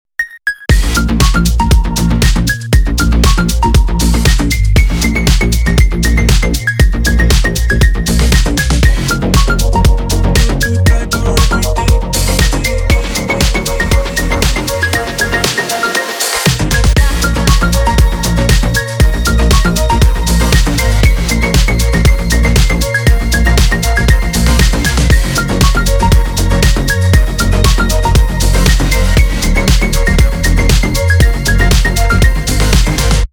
Рингтоны DubStep
без слов ритмичные